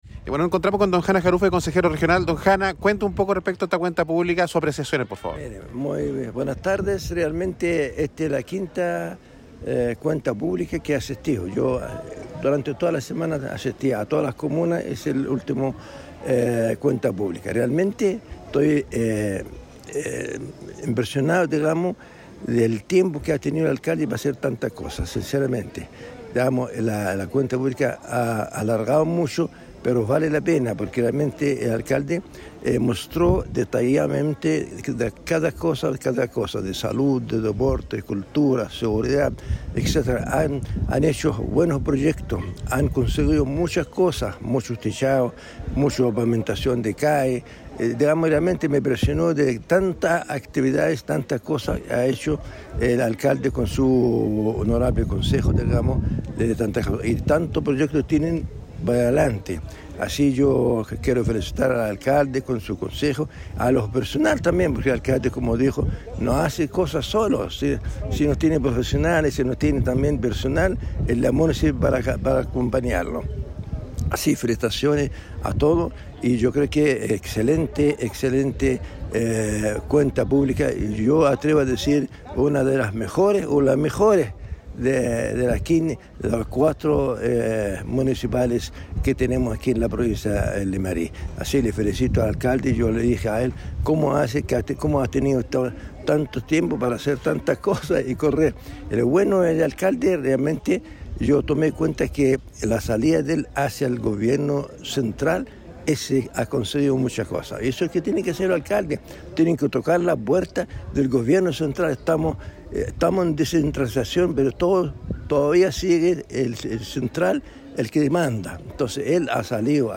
Como representante de los Consejeros Regionales, estuvo presente Don Hanna Jarufe Haune, quién al finalizar ésta Cuenta Pública señaló:
Hanna-Jarufe-CORE.mp3